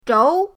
zhou2.mp3